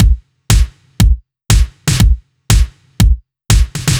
Index of /musicradar/french-house-chillout-samples/120bpm/Beats
FHC_BeatA_120-01_KickSnare.wav